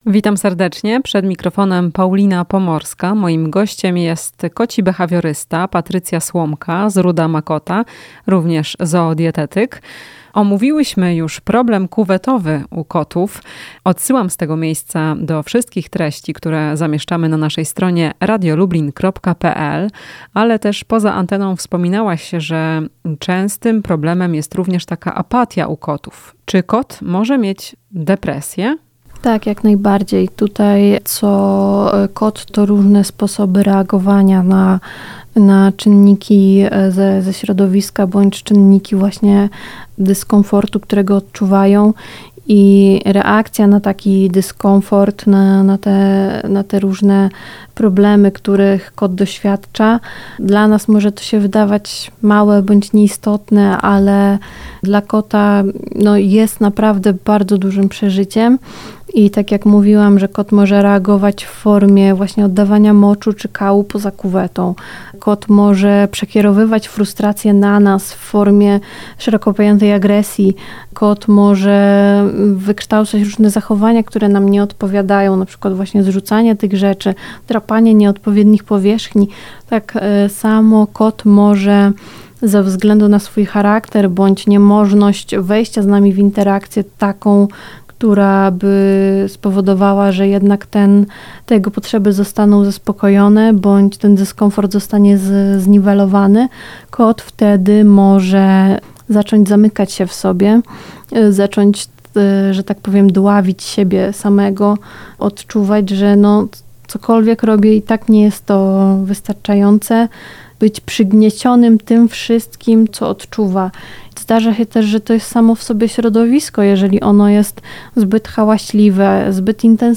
W "Chwili dla pupila" powiemy jak objawia się depresja u kota. Rozmowa